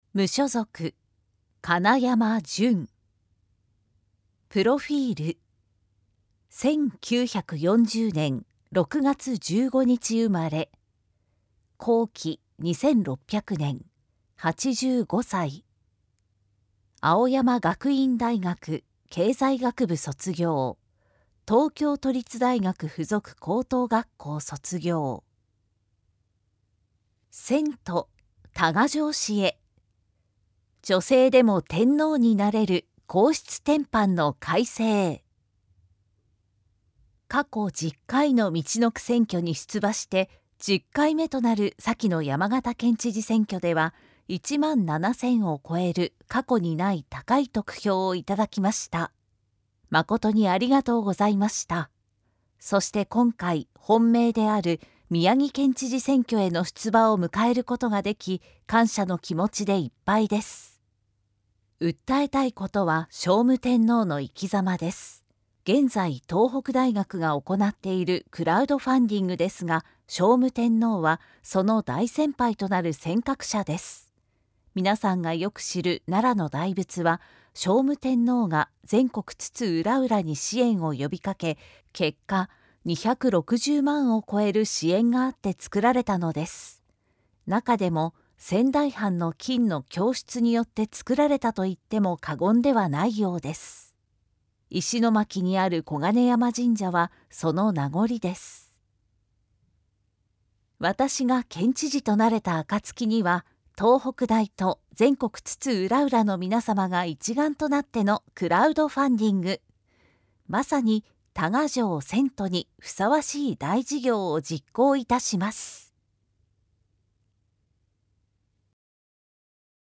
宮城県知事選挙候補者情報（選挙公報）（音声読み上げ用）